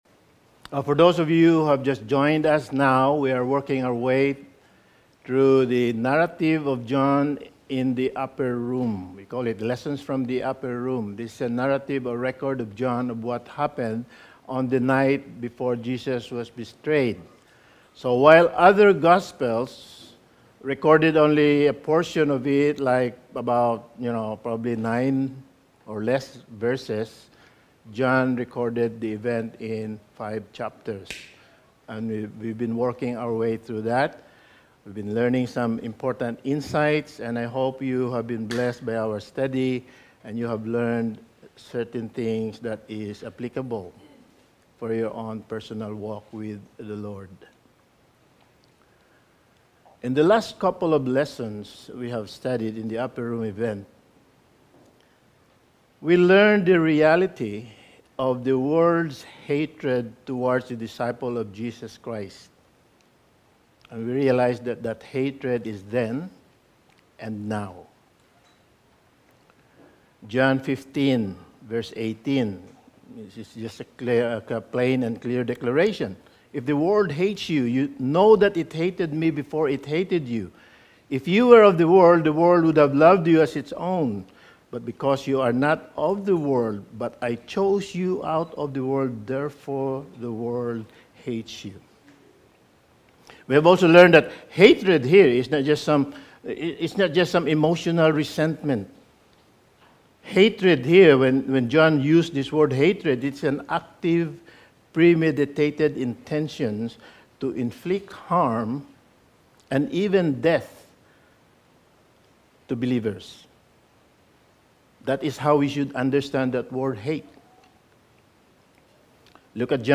Lessons From the Upper Room Series – Sermon 11: Confusion before Clarity; Sorrow before Joy
Service Type: Sunday Morning